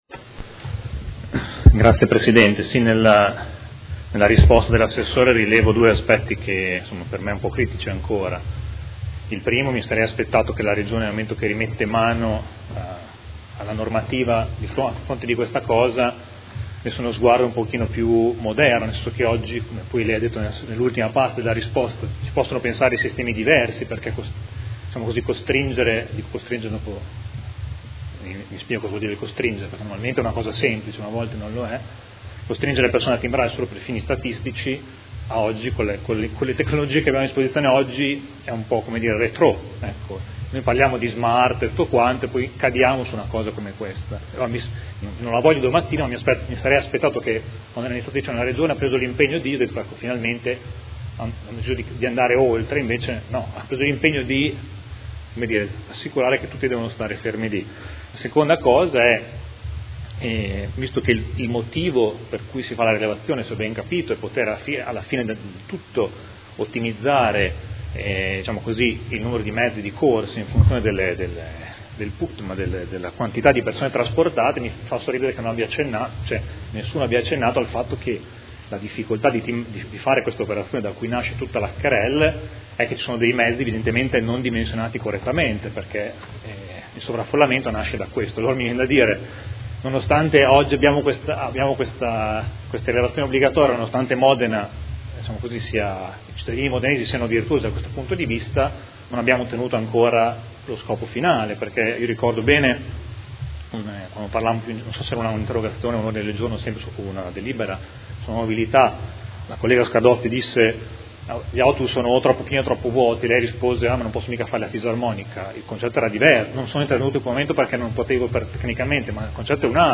Seduta del 27/10/2016 Dibattito. Interrogazione della Consigliera Arletti (P.D.) avente per oggetto: Multe ad abbonati SETA – occorre rivedere le regole di sanzionamento abbonati.